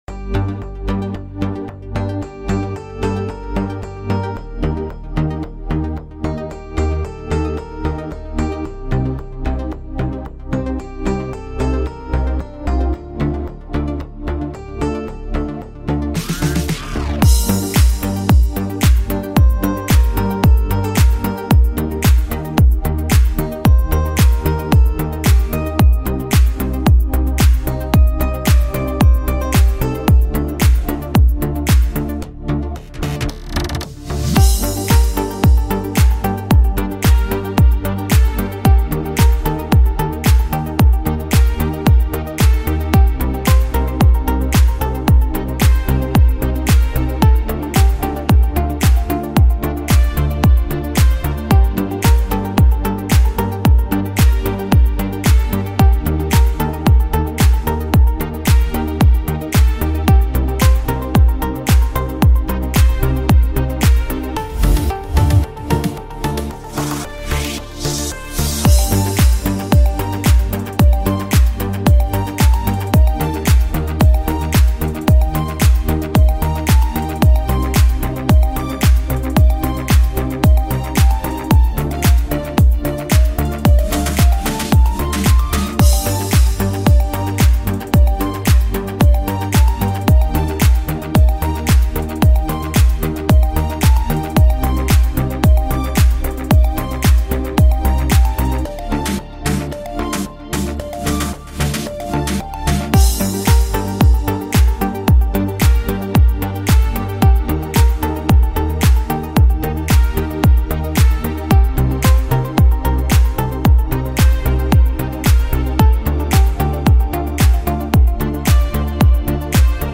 красивая музыка без слов